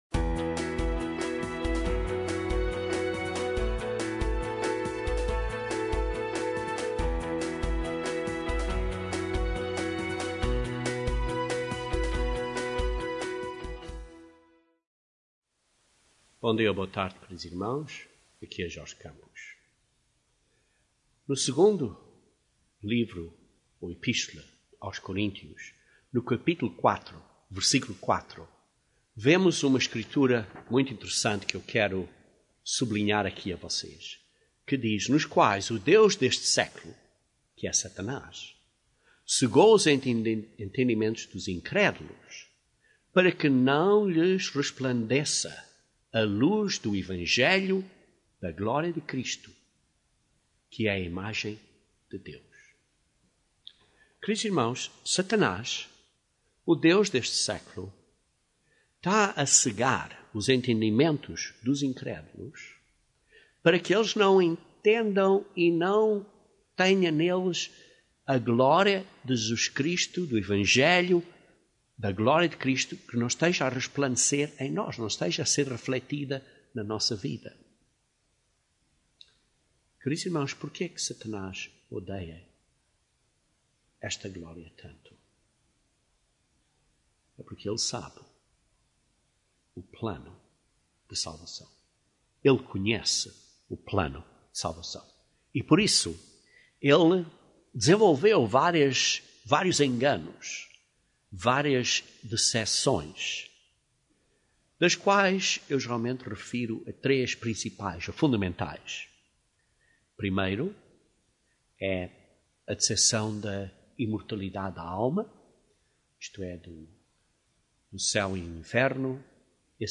Este sermão explica como o Plano de Salvação de Deus é diretamente relacionado com este grande mistério de Deus.